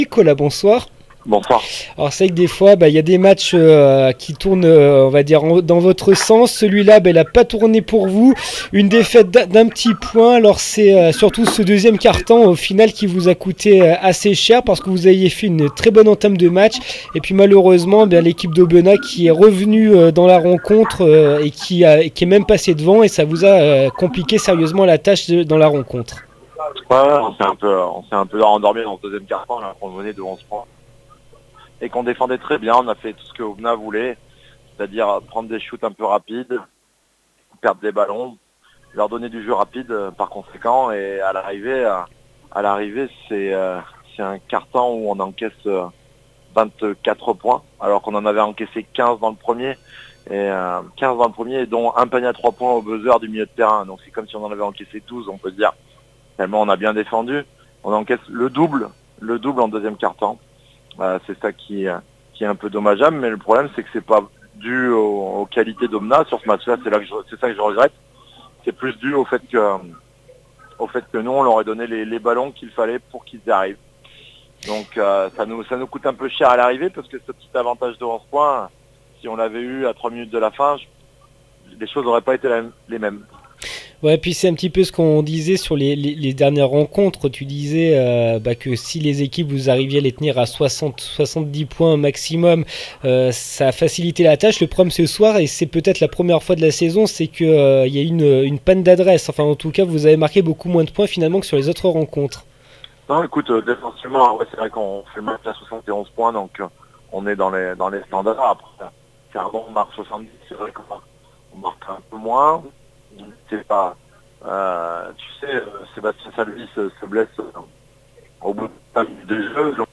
MATCH DE BASKET N2M AUBENAS-ASMB LE PUY 051215 REACTION APRES MATCH